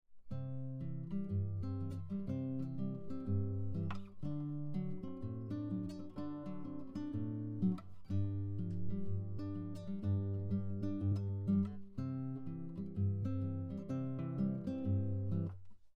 This fingerpicking style is characterized by a repeated bass pattern, while playing chords and melody at the same time.
This means that we are individually breaking up the notes in a chord to create a rhythmic sequence.
Travis picking pattern 5: Arpeggios